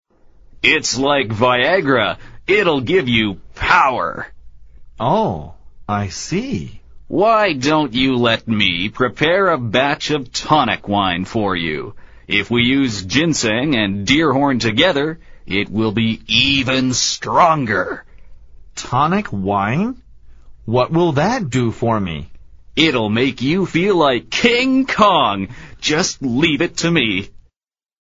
美语会话实录第76期(MP3+文本):Tonic wine 药酒